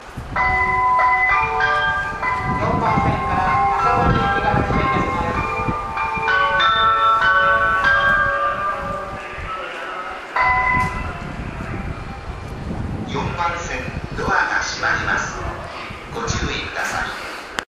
○発車メロディー○
スピーカーは１〜４番線がユニペックス大型、５〜８番線が小ボスです。 音質は基本的にどのホームも高音質で音量に関しても問題ないと思います。
発車メロディー1.1コーラスです。